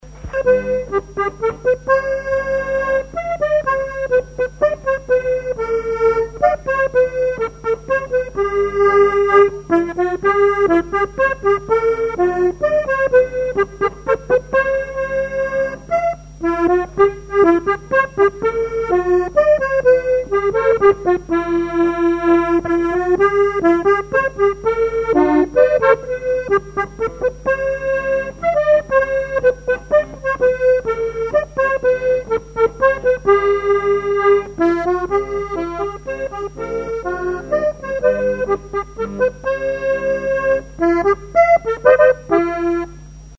danse : scottich trois pas
collectif de musiciens pour une animation à Sigournais
Pièce musicale inédite